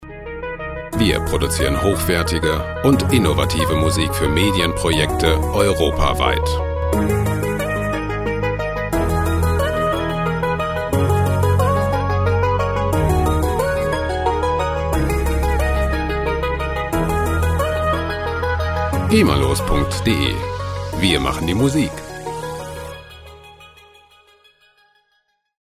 Electro Loops
Musikstil: Sequenzer Musik
Tempo: 90 bpm